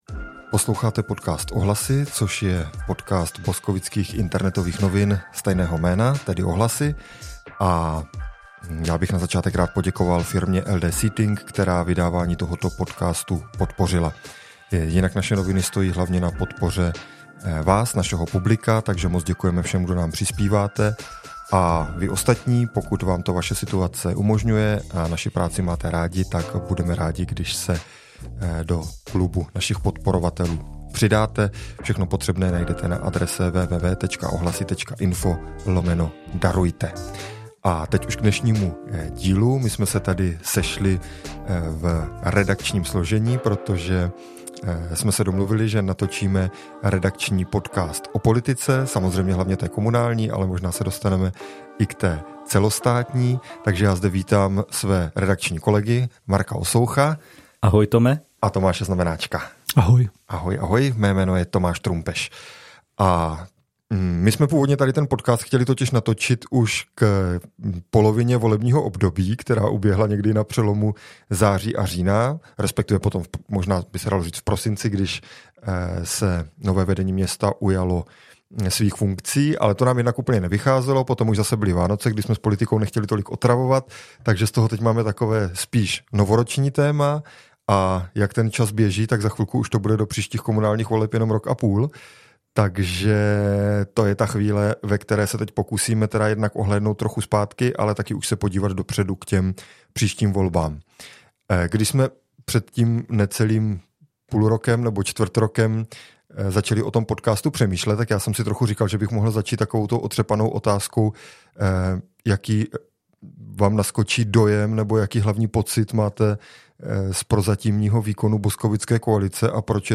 Redakční debata o politice